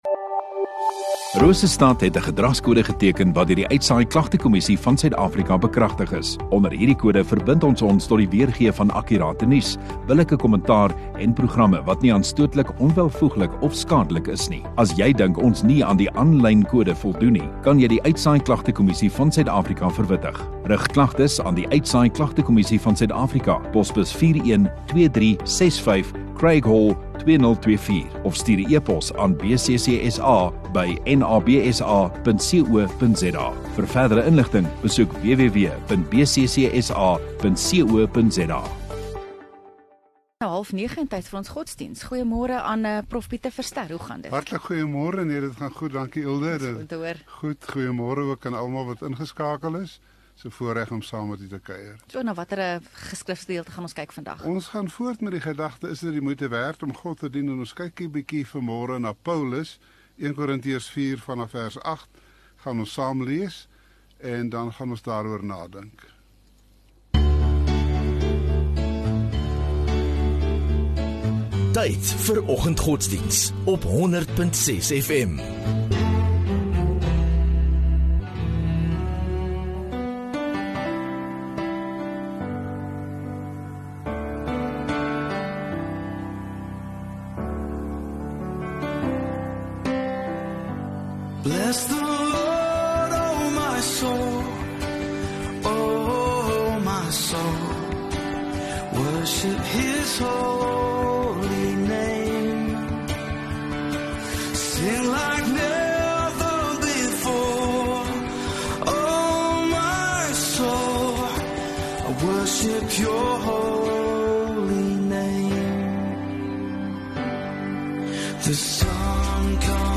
18 Jun Dinsdagoggend Oggenddiens